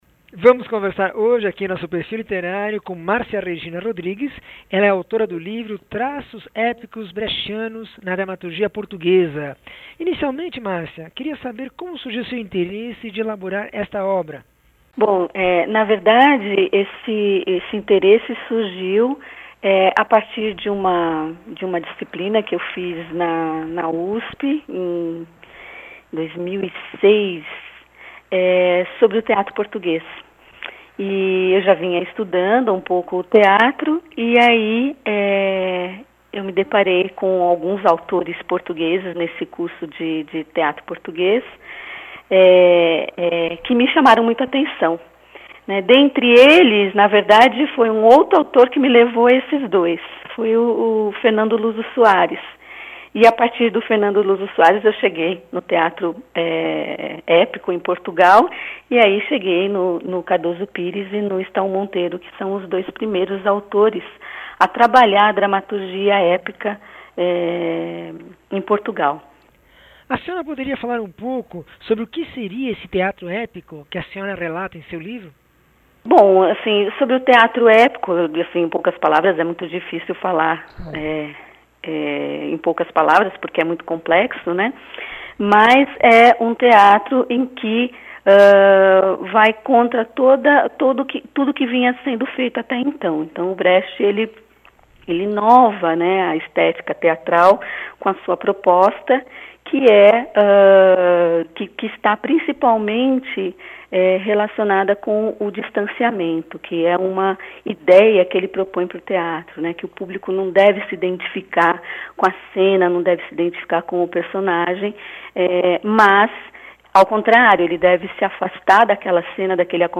entrevista 1117